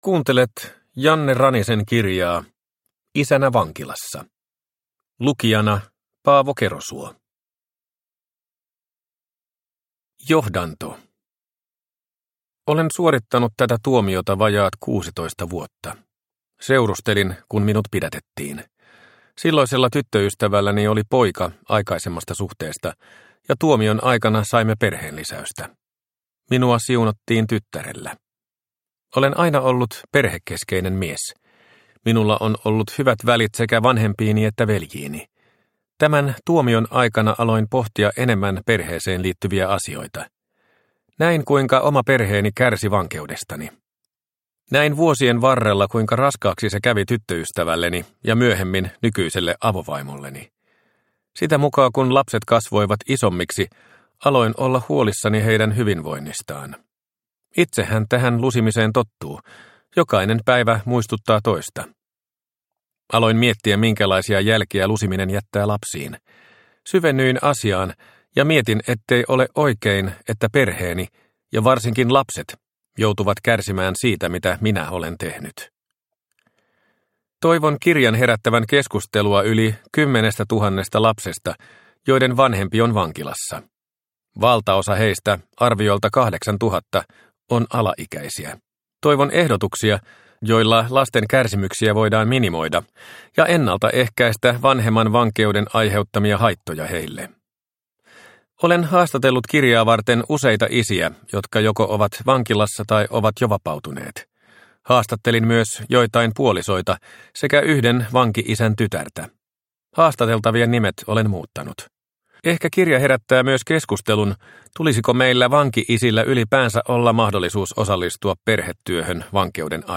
Isänä vankilassa – Ljudbok – Laddas ner